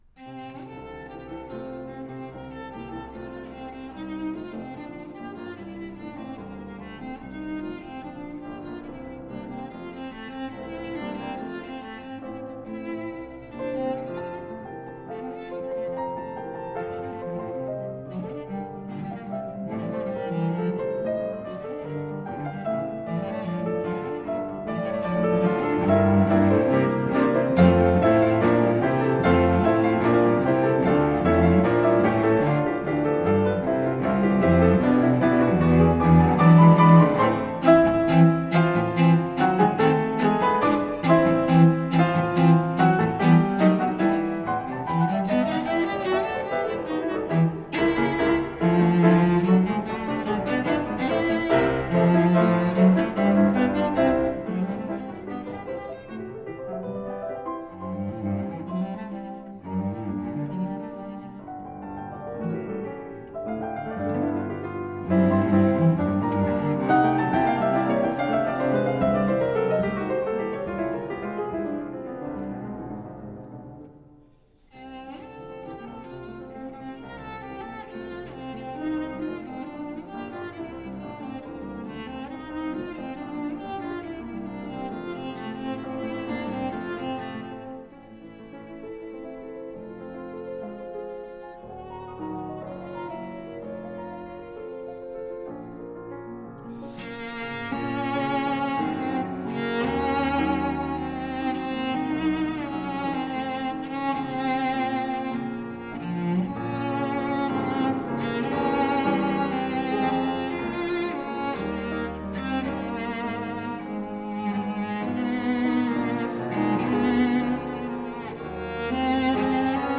Cello sonata